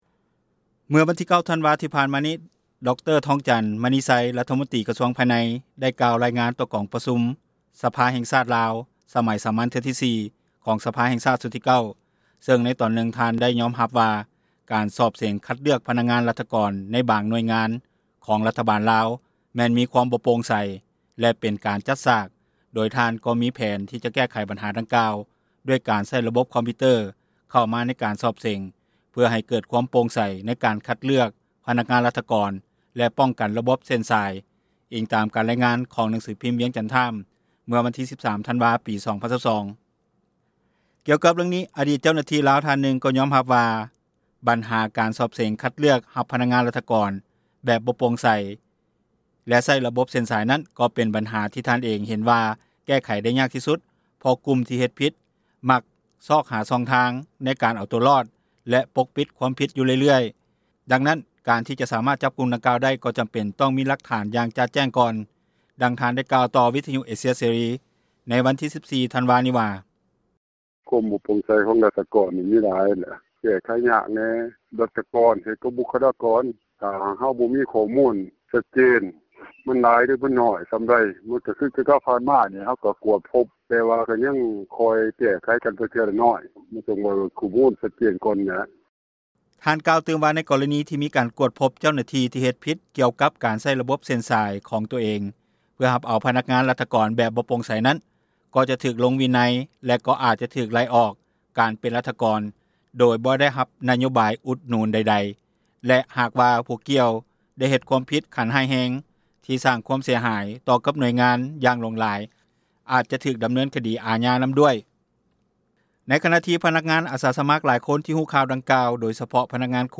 ດັ່ງພນັກງານ ຄຣູອາສາສມັກ ນາງນຶ່ງກ່າວວ່າ: